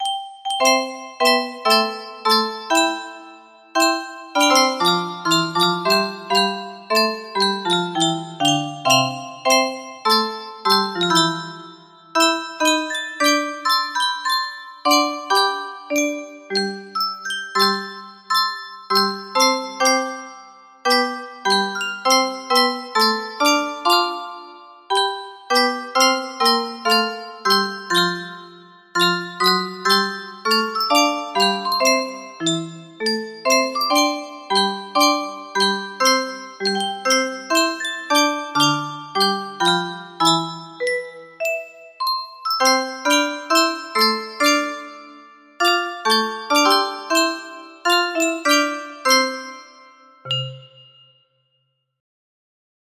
M L de Villiers - Stem van Suid Afrika music box melody
Full range 60